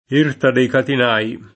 catinaio [katin#Lo] s. m.; pl. -nai — region. catinaro [katin#ro] — presso Firenze, l’Erta dei Catinai [